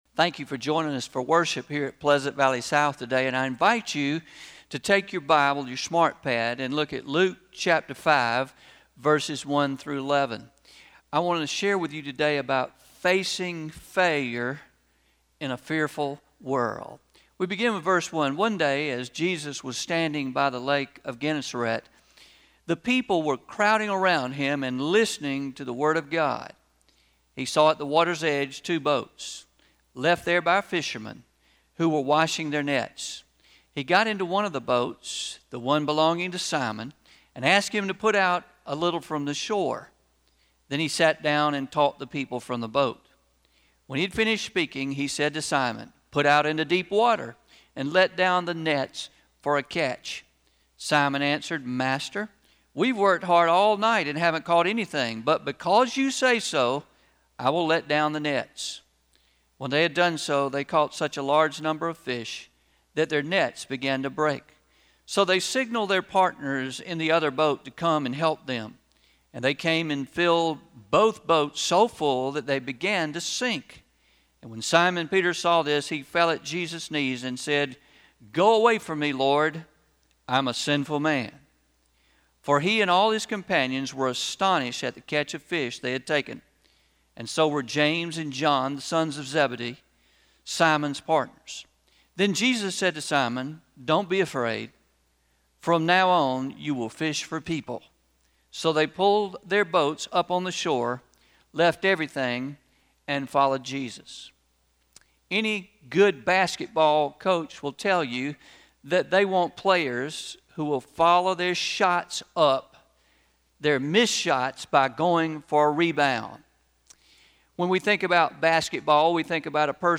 05-31-20am Sermon – Facing Failure in a Fearful World – Traditional